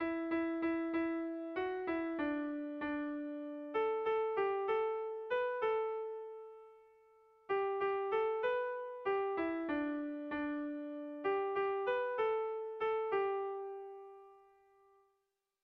Irrizkoa
Doinua 4ko txikia den arren, bertsoa 8ko txikia da.
Lauko txikia (hg) / Bi puntuko txikia (ip)
AB